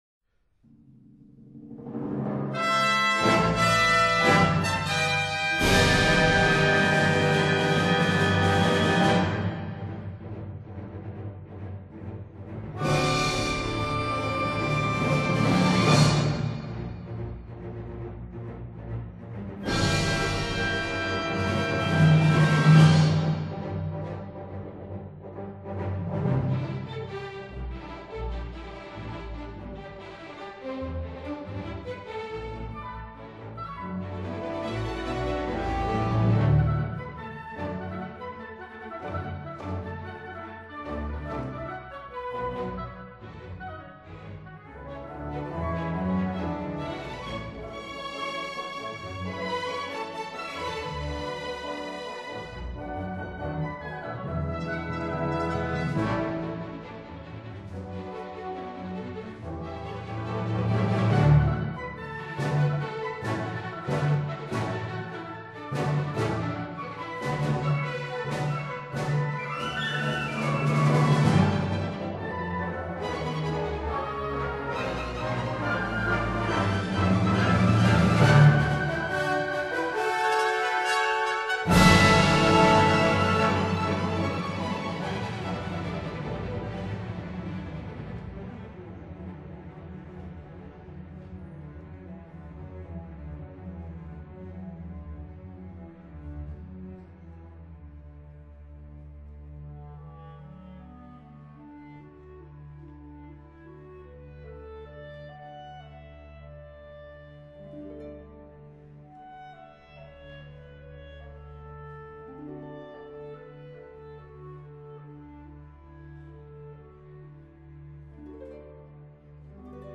乐曲形象鲜明，节奏明快，旋律优美，通俗易懂，是一部优秀的民族交响乐作品。